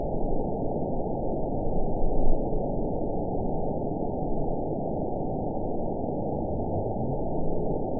event 917069 date 03/17/23 time 22:08:22 GMT (1 year, 1 month ago) score 7.59 location TSS-AB03 detected by nrw target species NRW annotations +NRW Spectrogram: Frequency (kHz) vs. Time (s) audio not available .wav